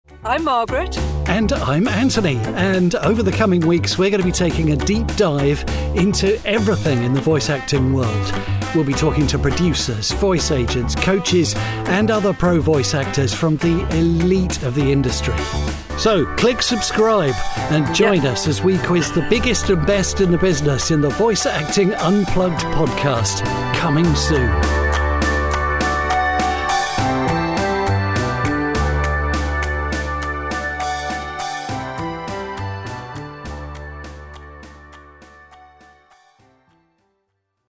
Experienced, conversational voice actor with warmth and integrity.
Professional Sound Booth